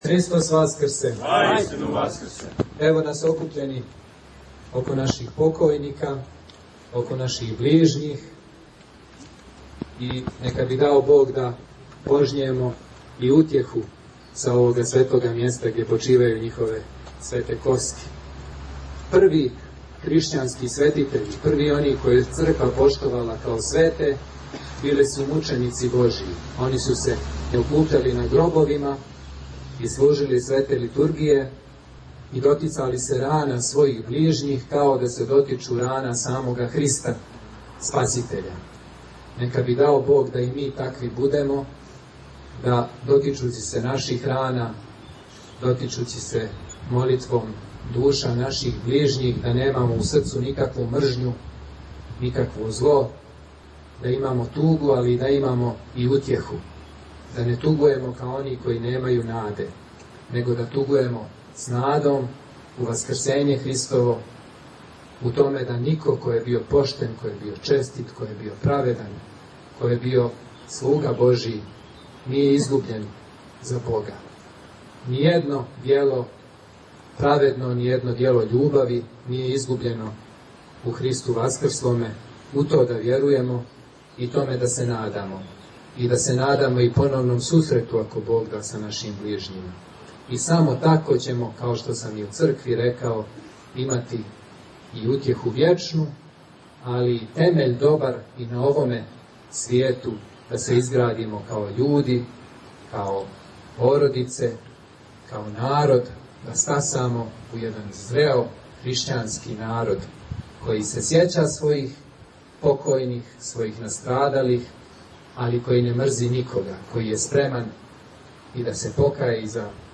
Владика Димитрије началствовао је Светом Литургијом у храму Вазнесења Господњег у Брадини код Коњица.
Звучни запис беседе